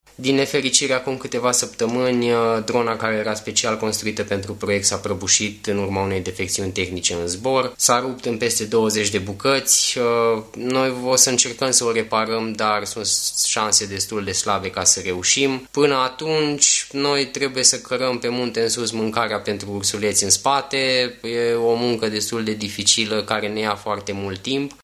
unul dintre îngrijitorii ursuleților ne spune mai multe: